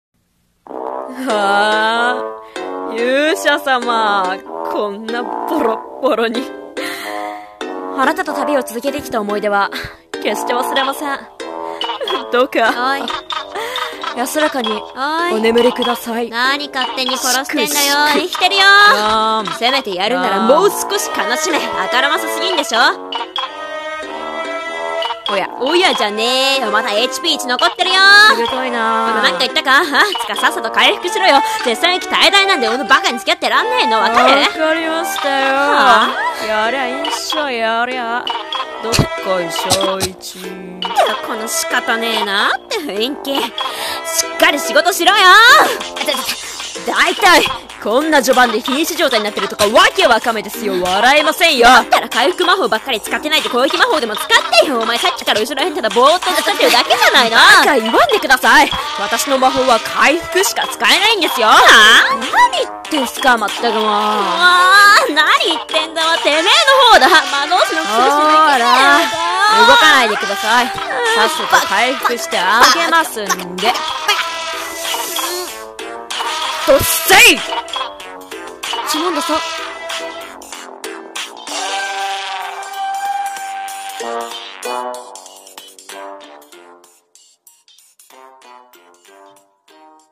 【声劇】ドッセイ